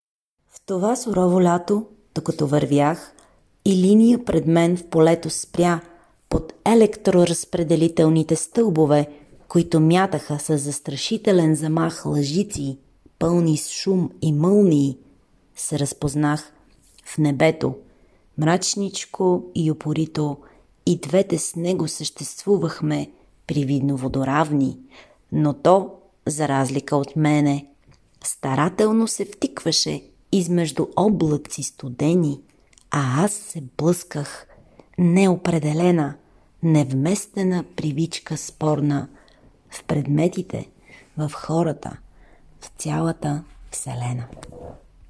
Припомням и оригинала, прочетен от